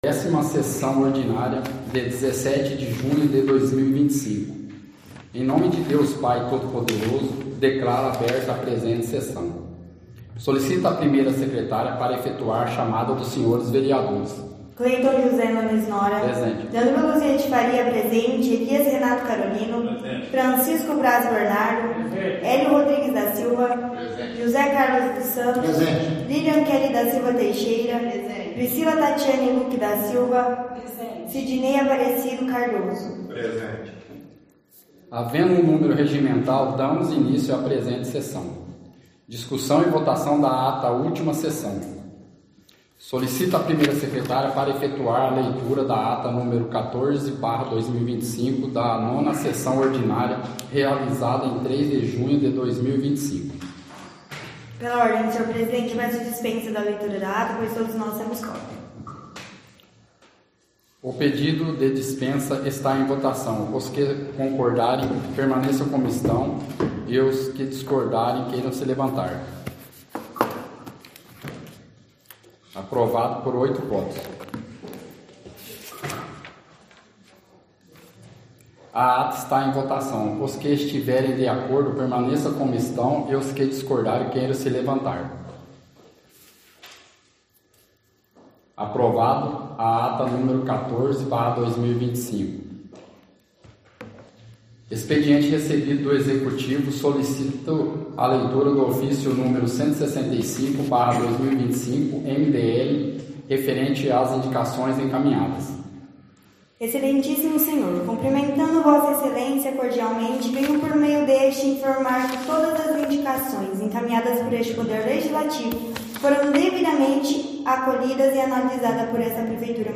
Áudio da 10ª Sessão Ordinária – 17/06/2025